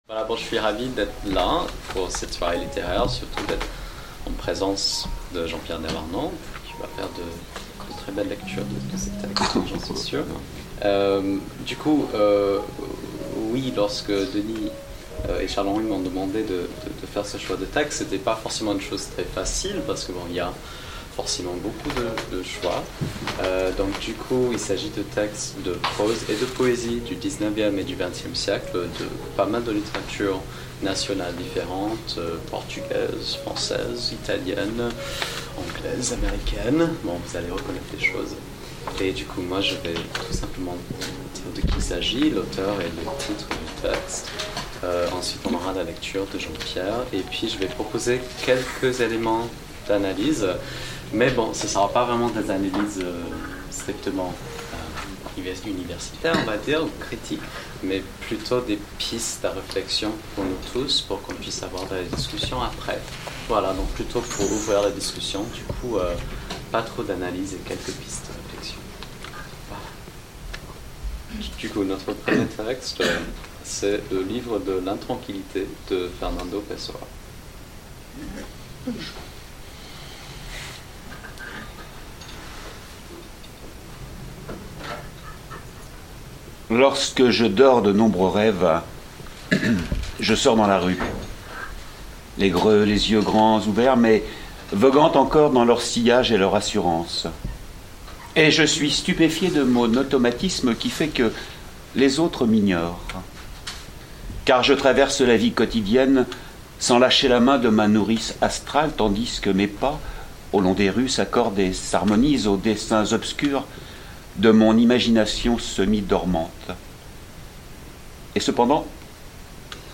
Atelier: lecture